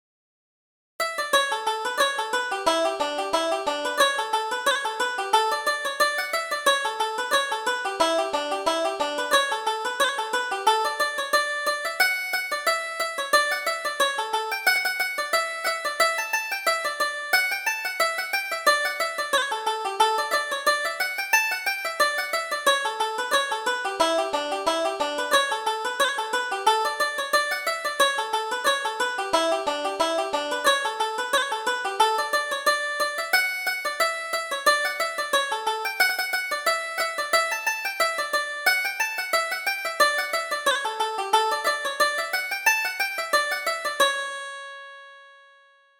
Reel: The Bank of Ireland